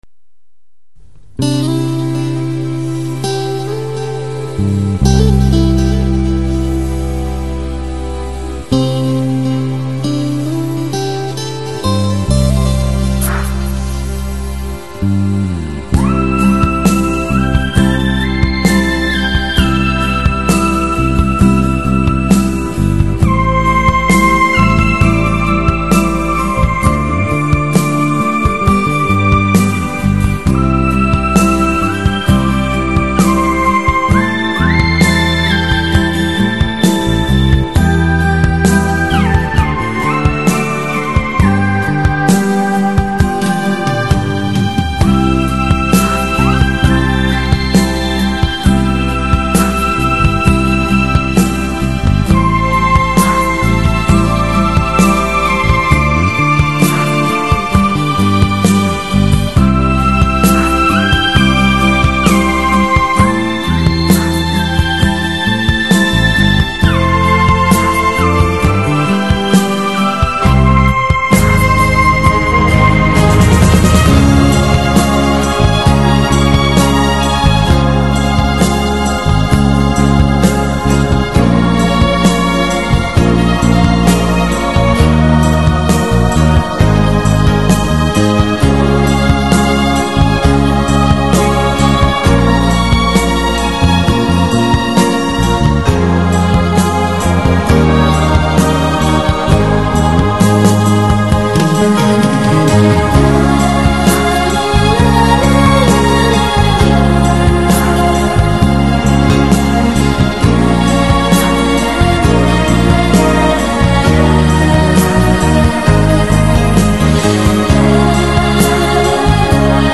由磁带转录，杂音较大，请下载后用软件修理。